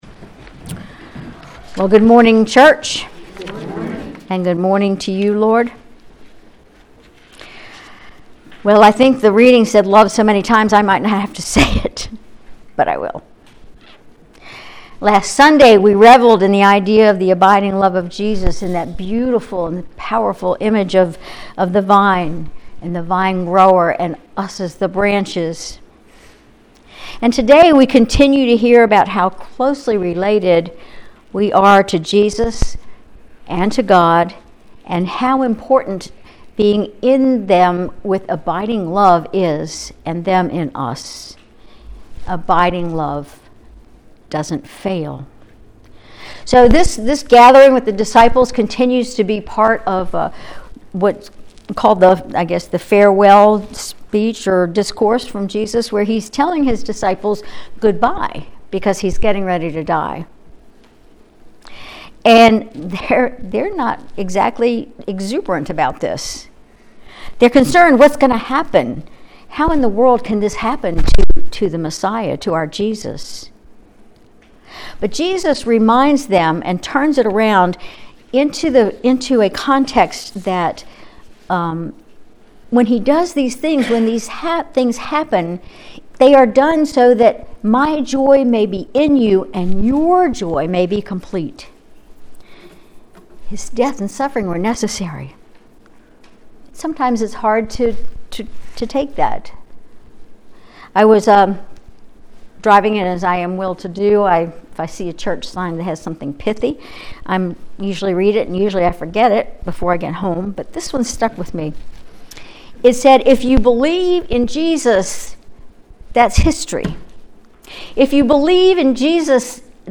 Sermon May 5, 2024